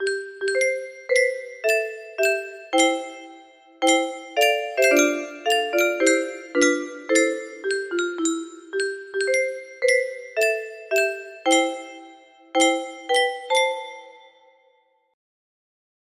Die stem 2 - 100 notes music box melody